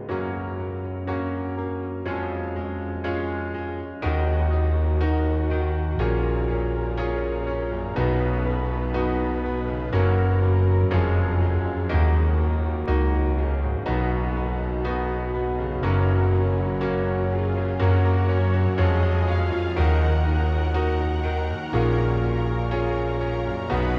One Semitone Down Soft Rock 3:29 Buy £1.50